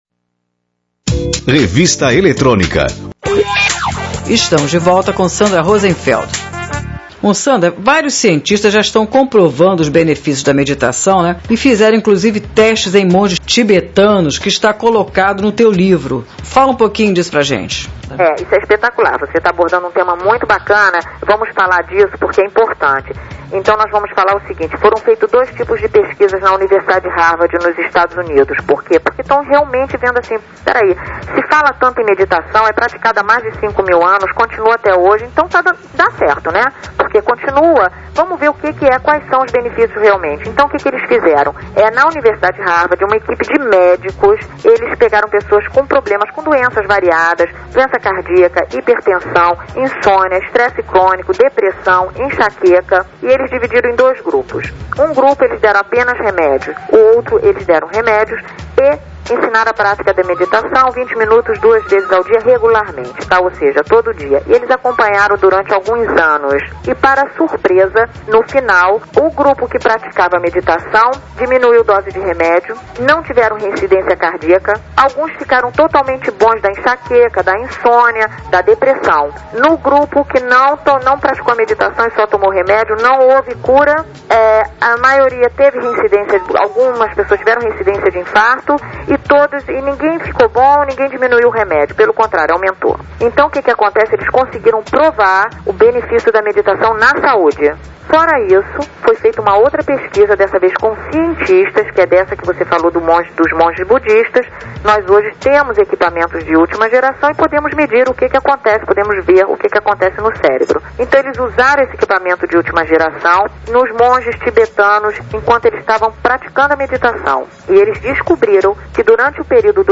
Entrevista: Programa Revista Eletrônica Rádio CBN Recife - PE: Parte 1 (5 min.)